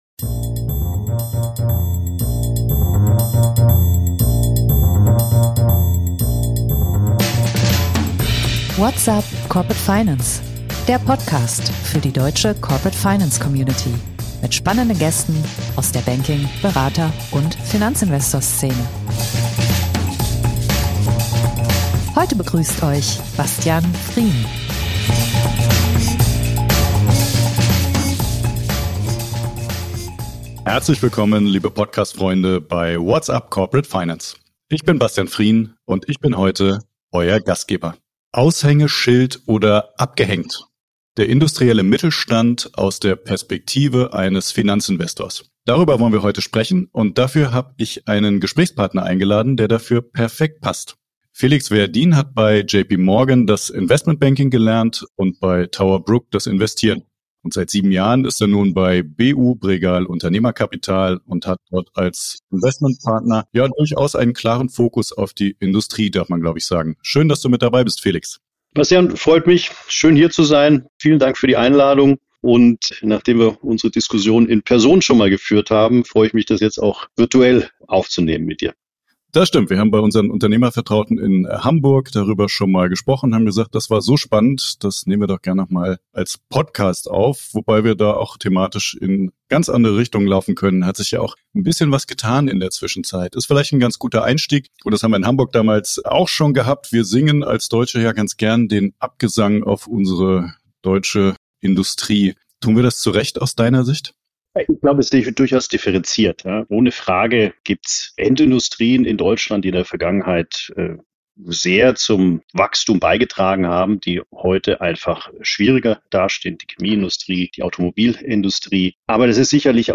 Wir sprechen in dieser Episode mit einem erfahren Finanzinvestor über Chancen und Risiken von Industrieunternehmen und über den allgemeinen Zustand der Branche.